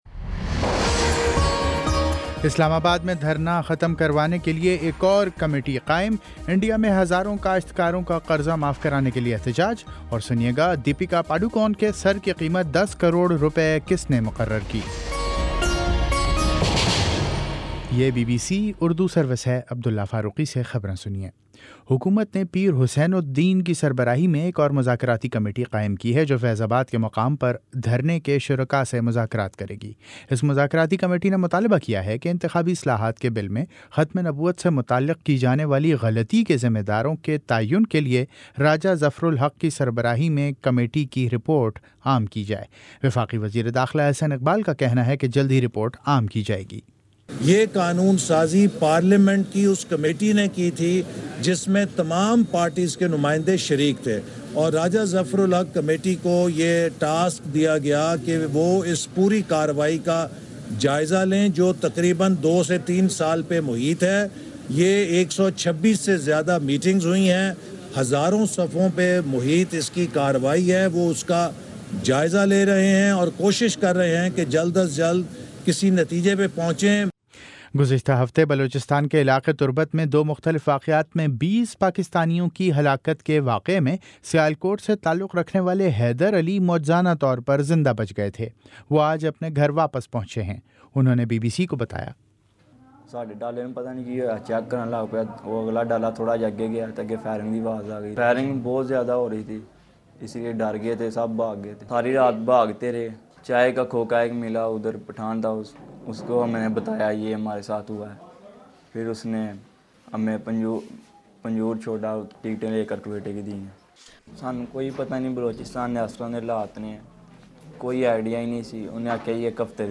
نومبر20 : شام سات بجے کا نیوز بُلیٹن